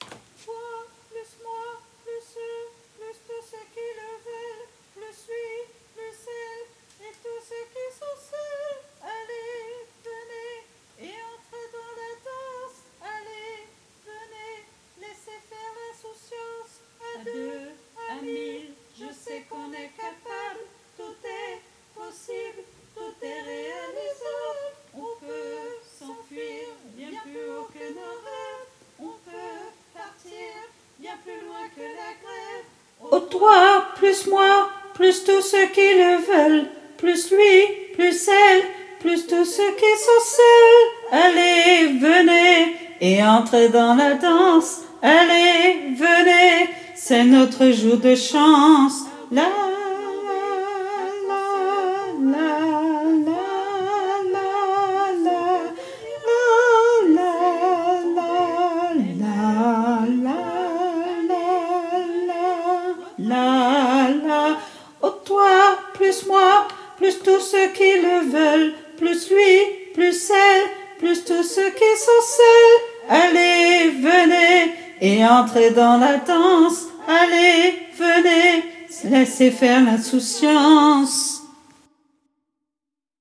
MP3 versions chantées
Hommes